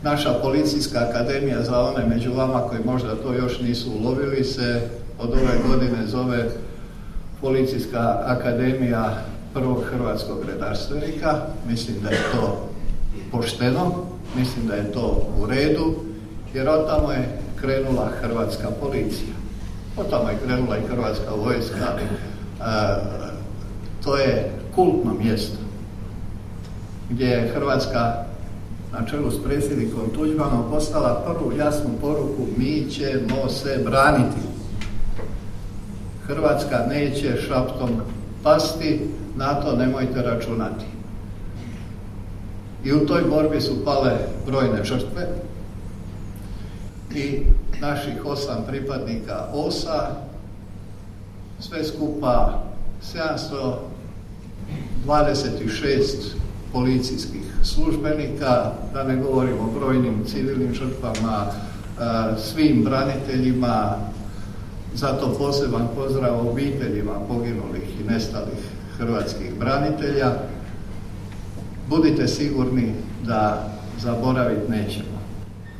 U srijedu, 15. ožujka 2023. godine, u Sisku u Hotelu Panonija svečano je obilježena 32. obljetnica osnutka Specijalne jedinice policije „OSA“.